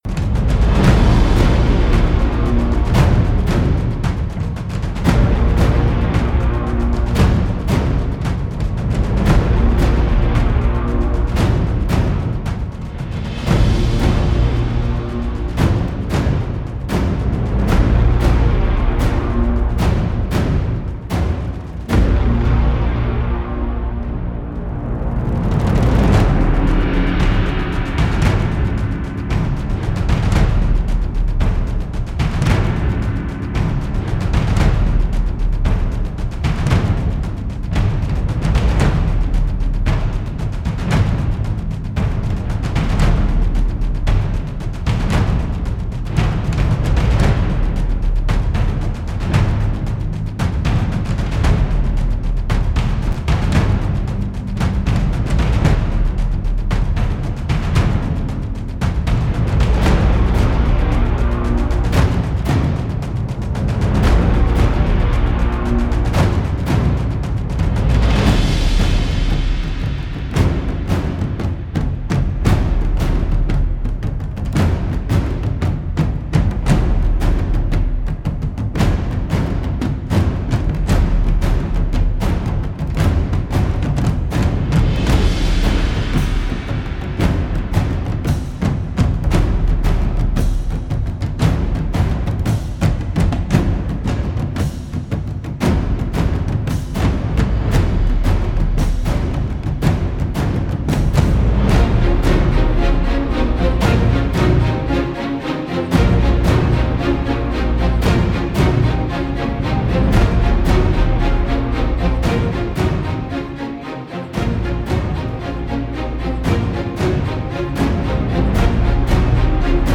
first you only hear these thrumming, oppressive war drums